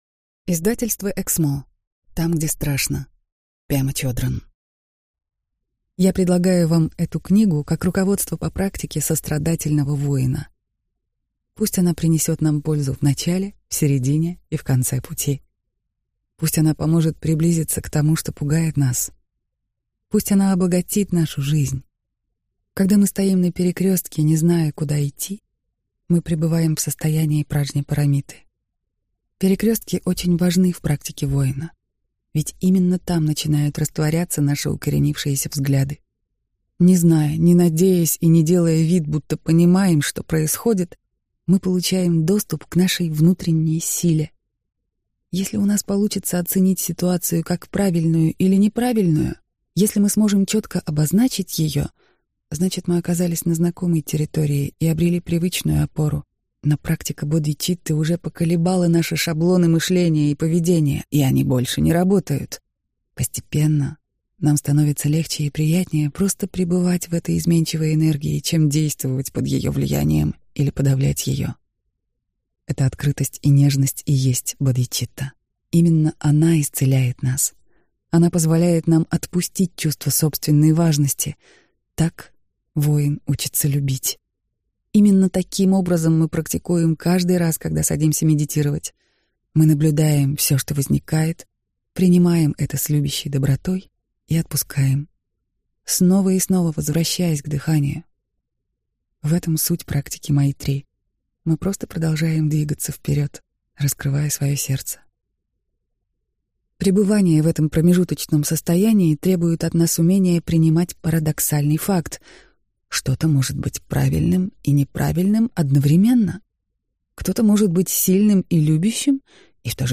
Аудиокнига Там, где страшно | Библиотека аудиокниг